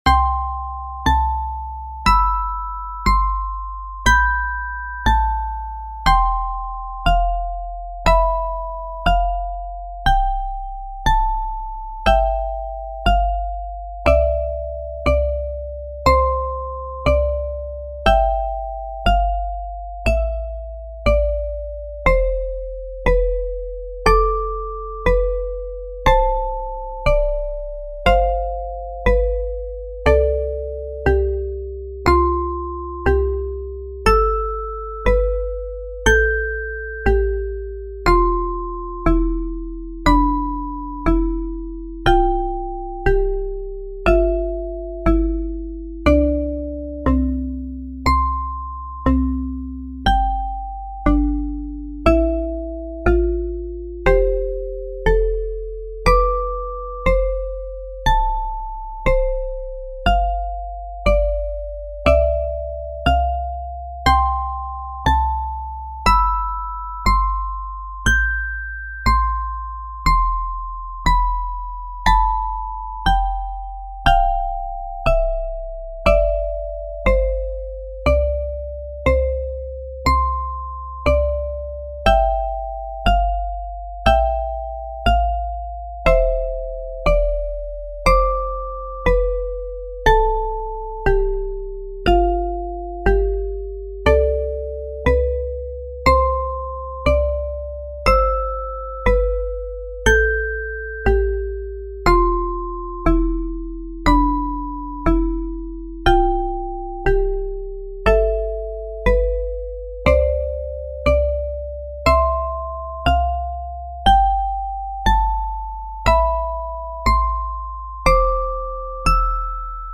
高音。オルゴール。ループ対応。